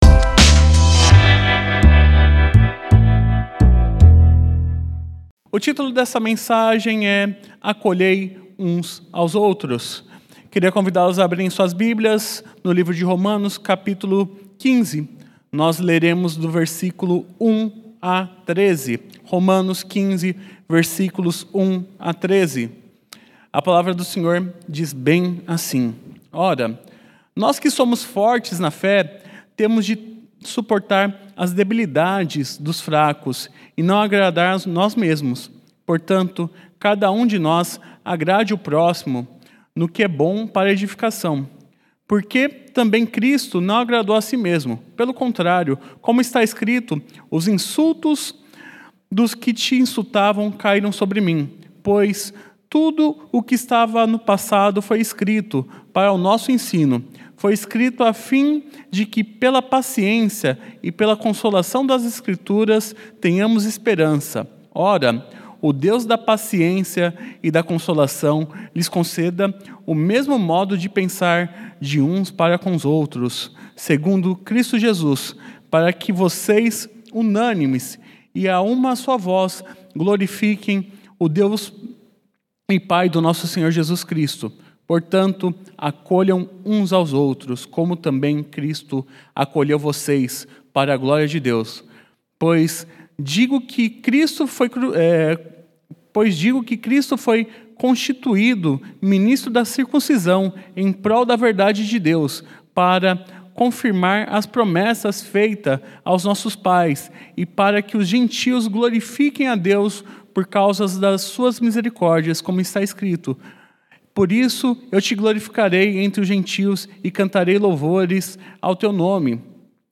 Uns aos outros: Acolhei uns aos outros – Romanos 15:1-13 (Preletor